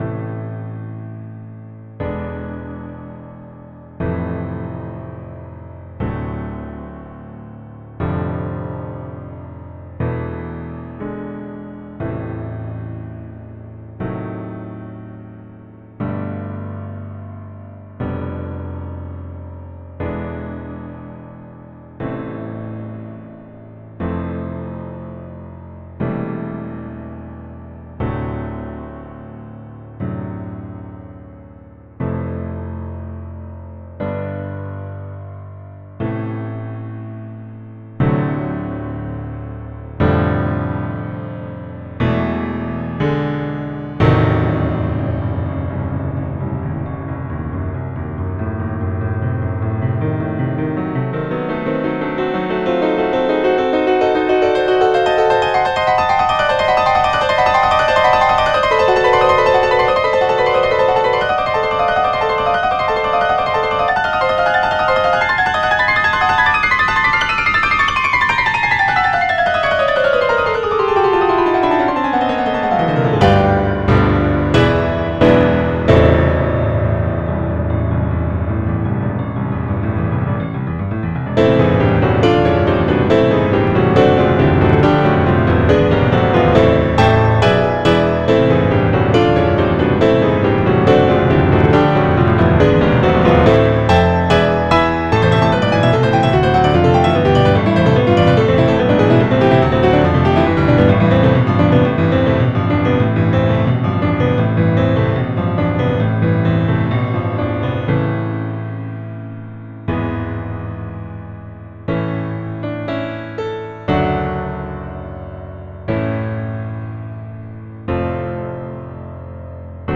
So, I've been working on this sonata for 5 months and i got to the point where i was stuck and could not think of anything for the third movement.